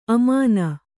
♪ amāna